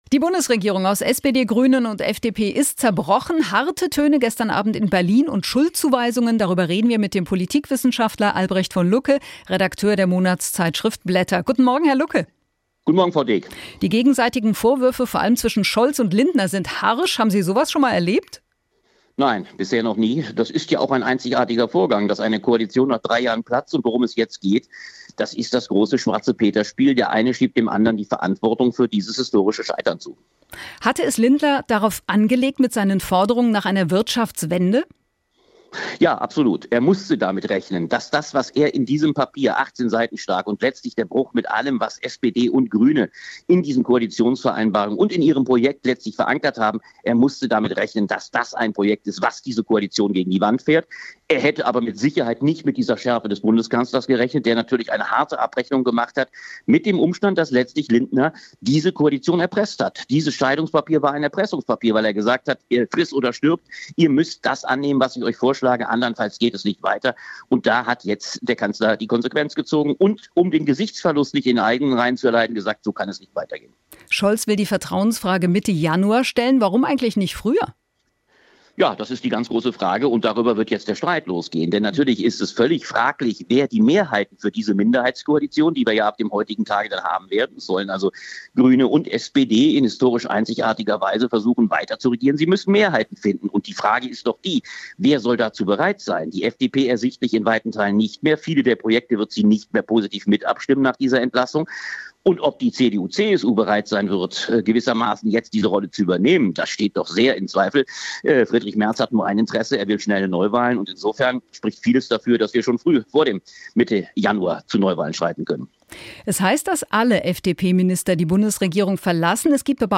Politikwissenschaftler über politischen Supergau